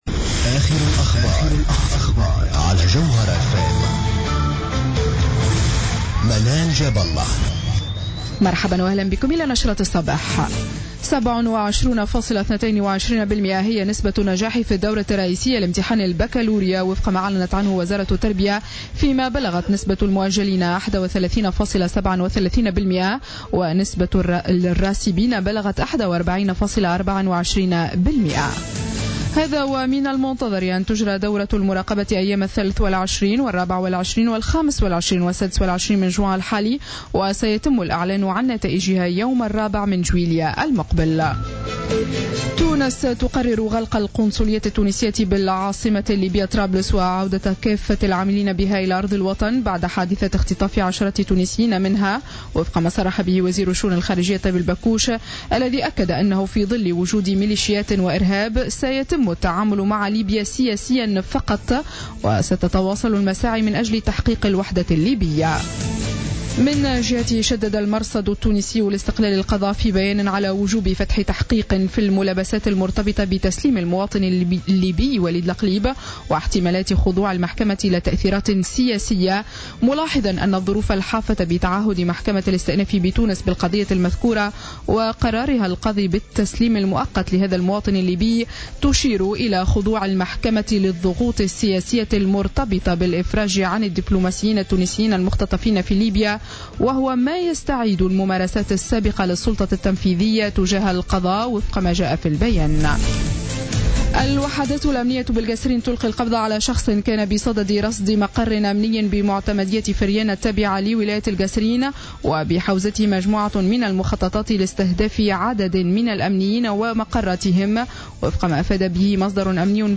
نشرة أخبار السابعة صباحا ليوم السبت 20 جوان 2015